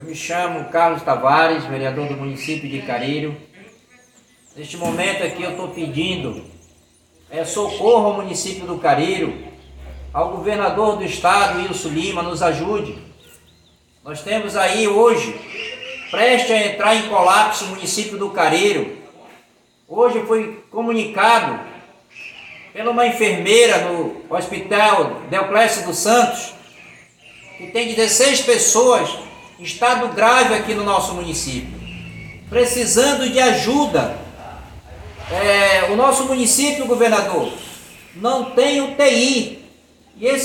Ouça o áudio do vídeo do vereador a seguir:
Carlos Tavares – Vereador do Município do Careiro